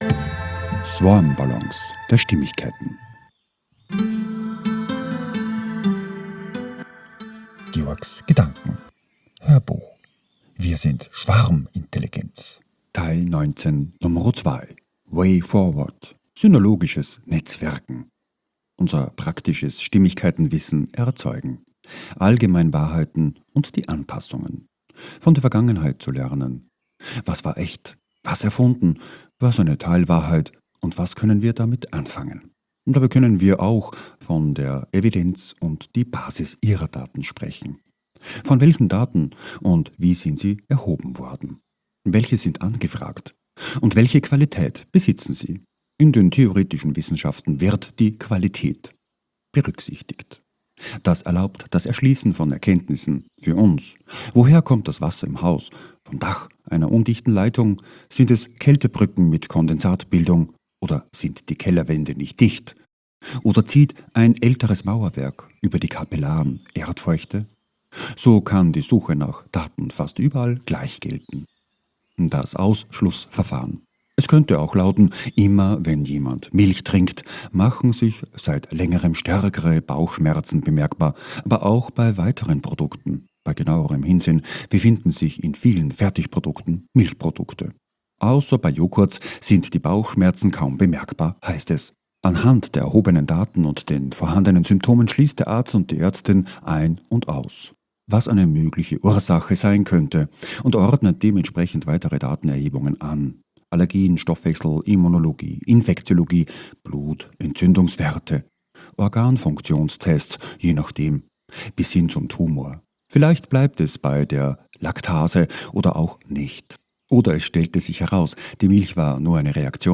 HÖRBUCH - 019.2 - WIR SIND SCHWARMINTELLIGENZ - WAY FORWORD - SYNERLOGI(E)sches NETZWERKEN